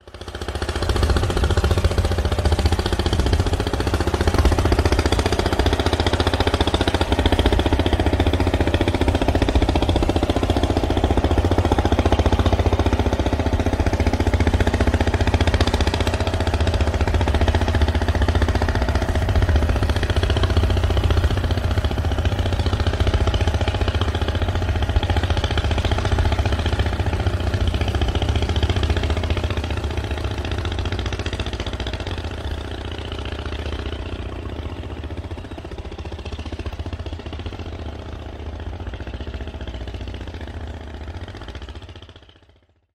Tiếng Thuyền máy, Đò máy… chạy trên sông
Thể loại: Tiếng xe cộ
Description: Tiếng thuyền máy, đò máy, ghe máy từ xa vọng lại, nhè nhẹ, rền rĩ... tiếng động cơ nổ đều, gằn gằn, đặc trưng của loại tàu nhỏ chạy trên sông. Khi chiếc thuyền máy lướt ngang qua, âm thanh rõ ràng, rồi dần nhỏ lại theo từng vòng quay của chân vịt – tiếng đò máy xa dần, lẫn vào khoảng không xa xăm. Âm thanh giảm dần, loãng ra, rồi tan biến, để lại dư âm trầm lắng, gợi nhớ không khí miền quê sông nước yên bình.
tieng-thuyen-may-do-may-chay-tren-song-www_tiengdong_com.mp3